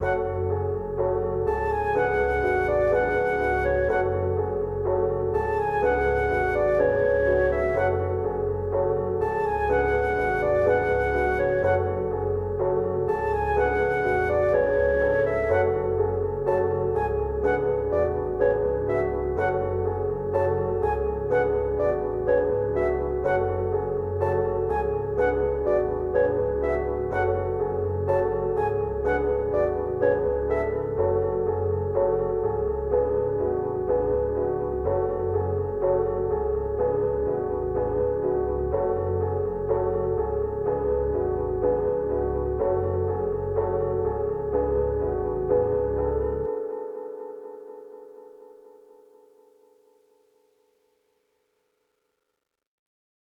124BPM, F# Minor